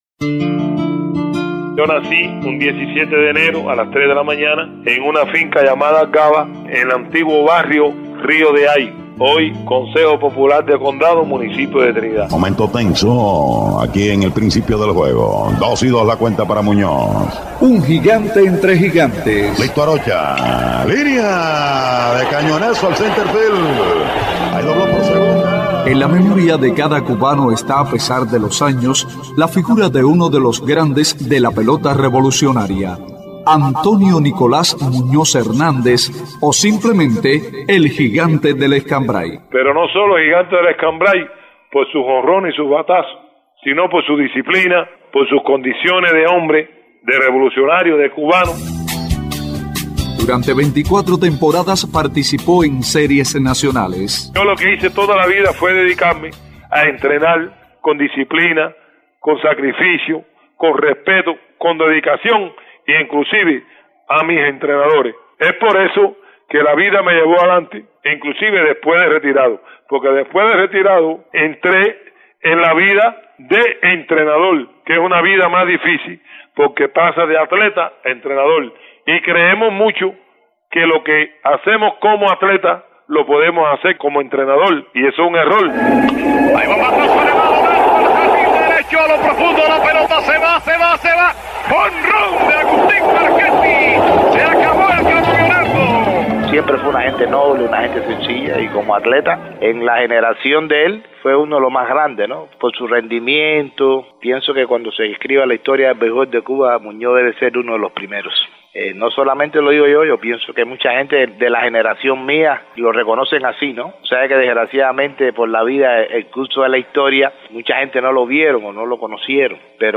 🎧 Festival de la Radio en Cienfuegos: Reportaje Un gigante entre gigantes